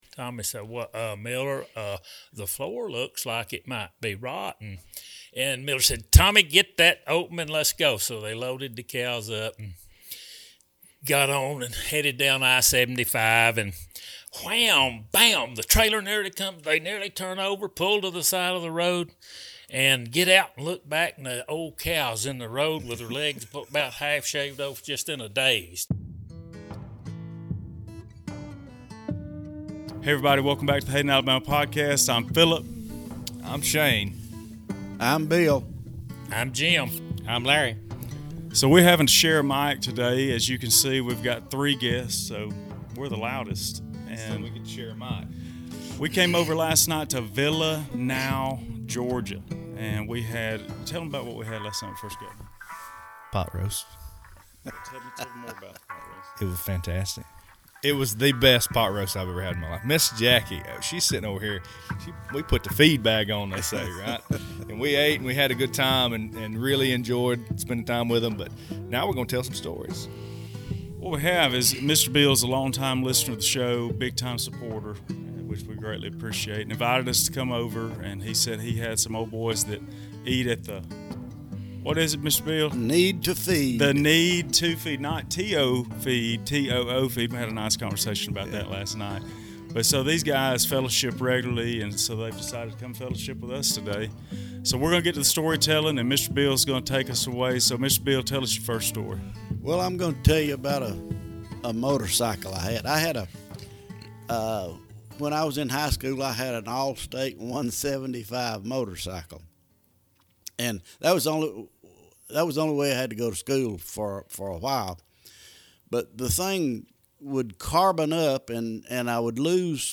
We traveled to Villanow, Georgia to tell some stories with a few friends from the show. This week had a wide variety of storytelling which we hope that you will enjoy as much as we did.